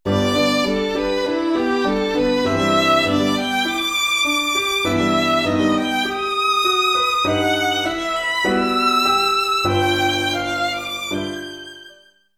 MIDIFaisst, Clara, Sonata for violin and piano, Op.14, mvt. I. Allegro animato, mm. 211-214